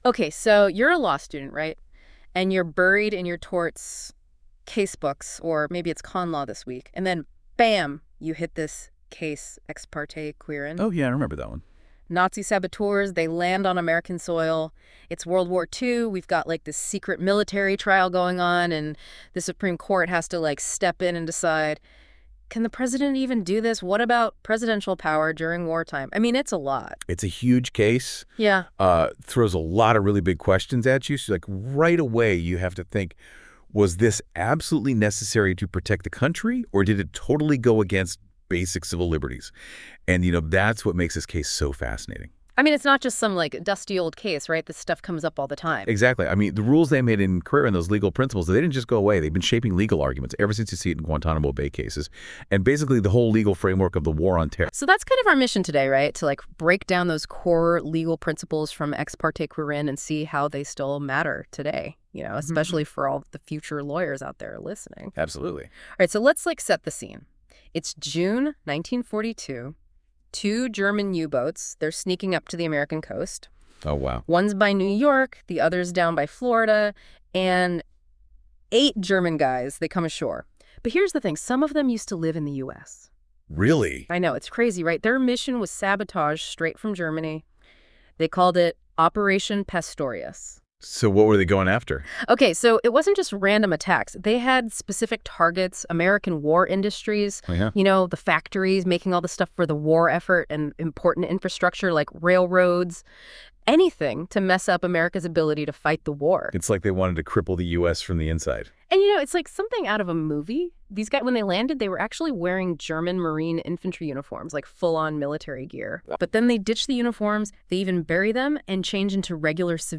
Listen to an audio breakdown of Ex parte Quirin.